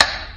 metronomelow.wav